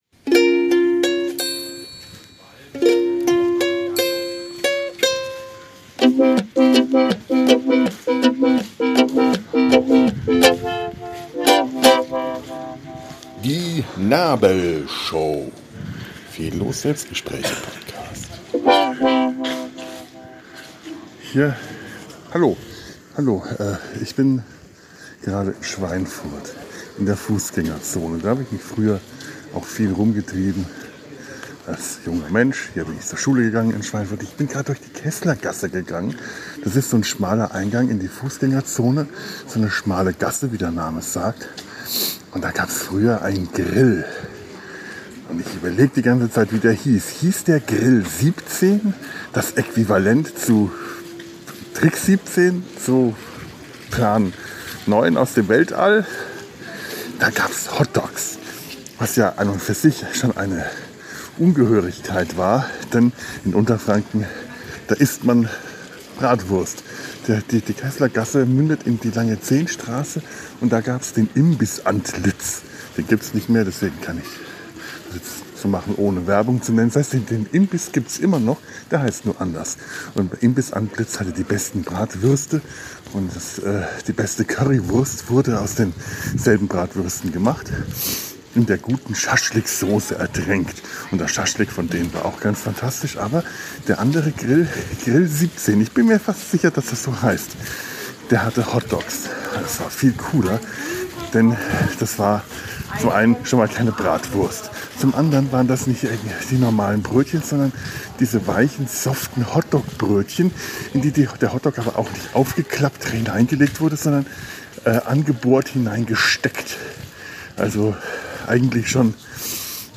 Ein kleiner Spaziergang durch die Schweinfurter Fußgängerzone